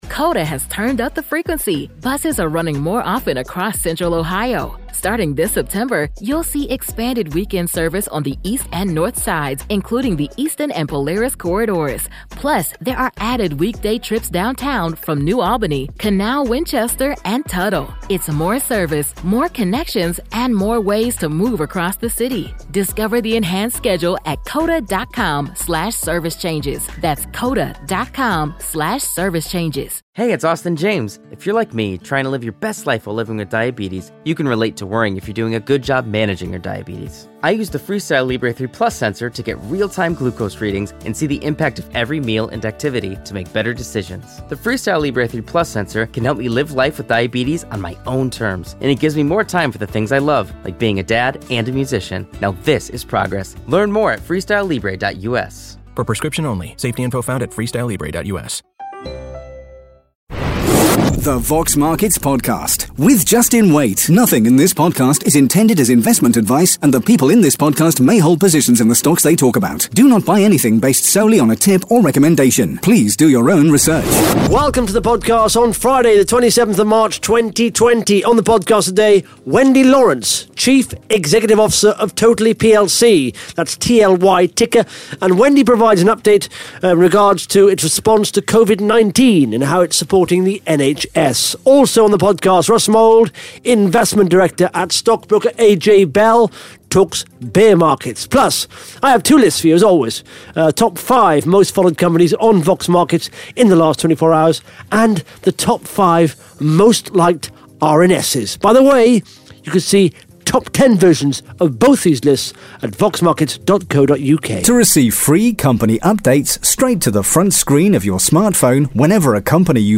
(Interview starts at 11 minutes 52 seconds) Plus the Top 5 Most Followed Companies & the Top 5 Most Liked RNS’s on Vox Markets in the last 24 hours.